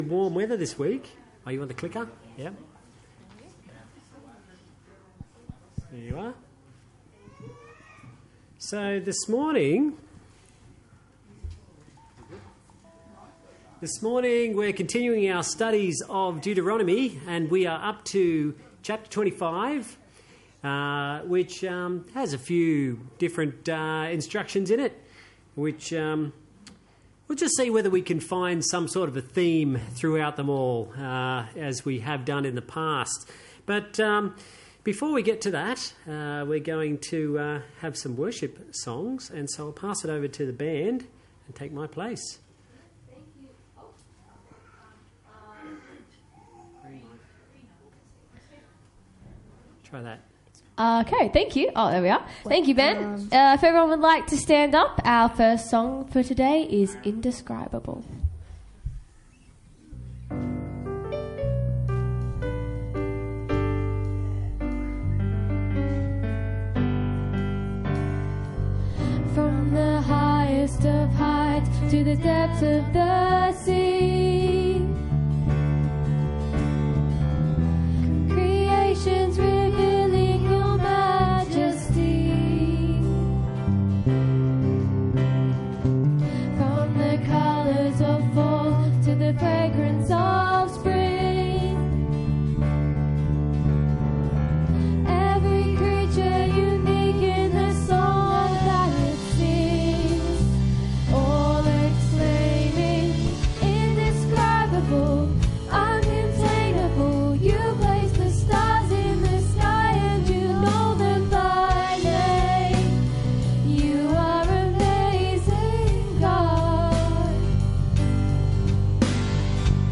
Deuteronomy 25 Service Type: Sunday Church Join us as we explore Deuteronomy 25